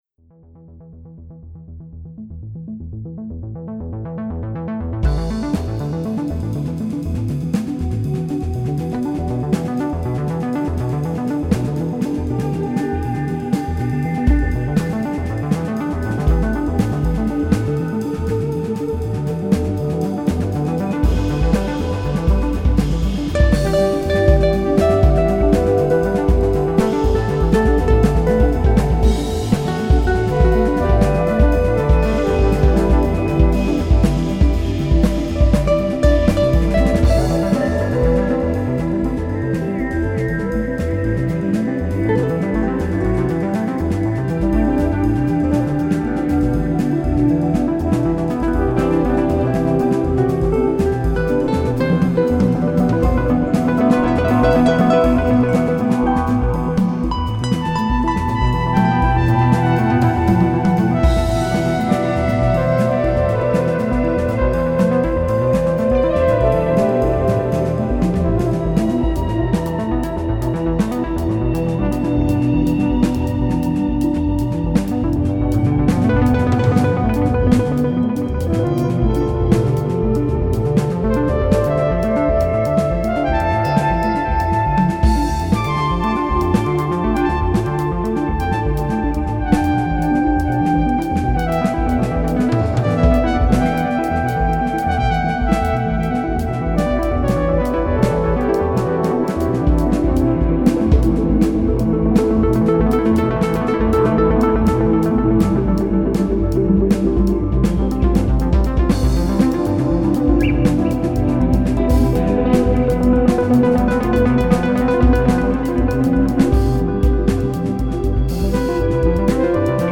Flöten, Saxophon, Gesang, Ukulele, Xalam, Perc.
Piano, Keyboards, Electronics
Ac.+ El. Guitars, Guitar Synthesizer, Perc.